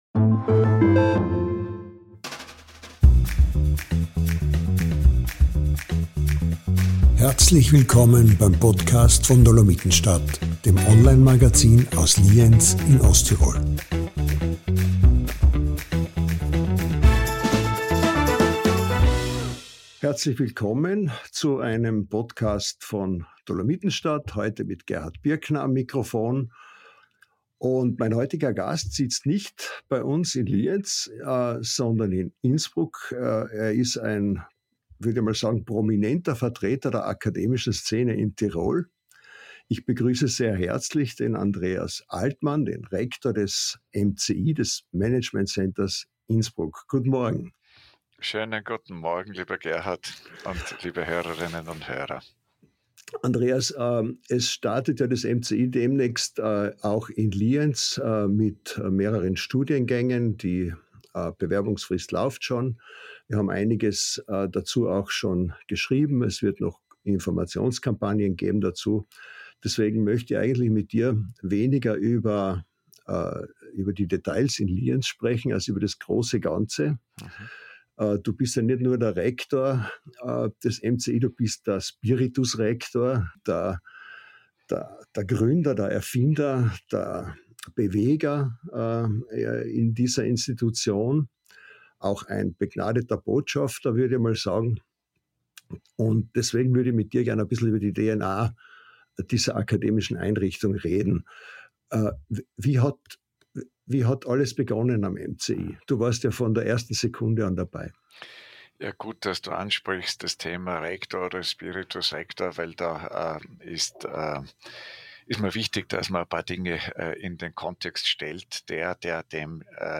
Im Gespräch geht es aber nicht nur um Erfolge, sondern auch um Rückschläge wie den abgesagten MCI-Neubau und um wissenschaftliche Ethik, Anwendungsorientierung in der Forschung, die Abgrenzung zu den großen Universitäten und die MCI-Pläne in Lienz.